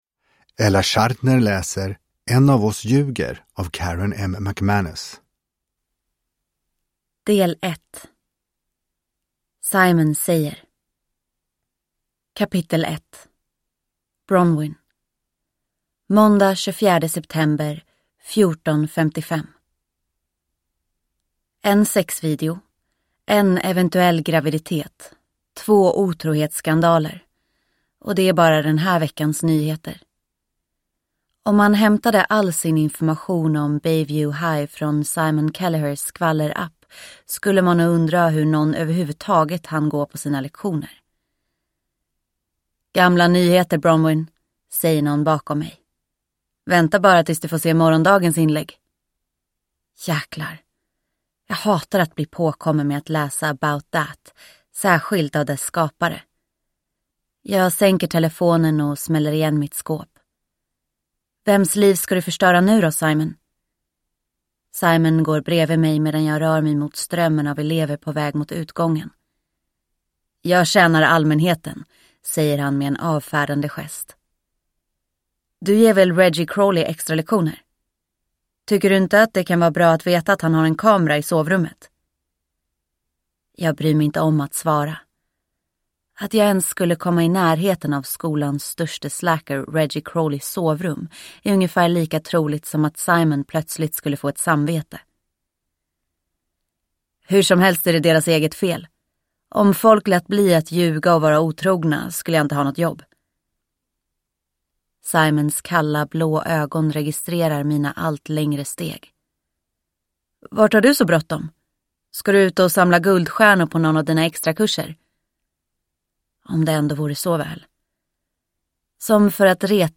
En av oss ljuger – Ljudbok – Laddas ner